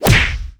PunchHit1.wav